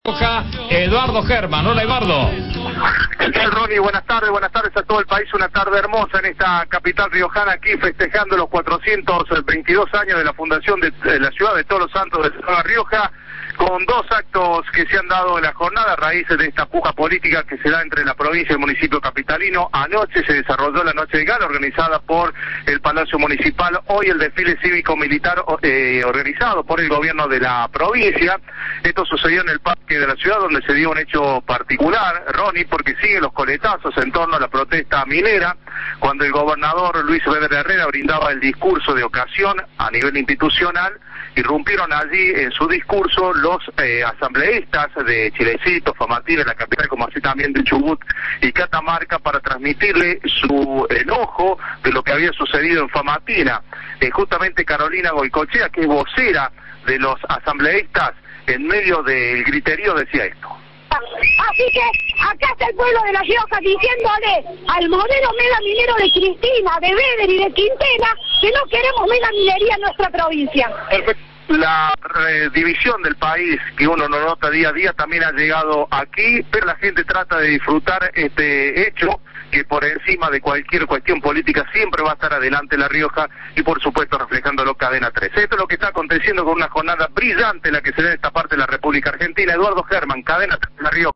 Fue en el marco de los festejos por los 422 años de La Rioja.
Un grupo de ambientalistas abucheó al gobernador de La Rioja, Luis Beder Herrera, en momentos en que formulaba su discurso institucional por el aniversario número 422 de la ciudad capital.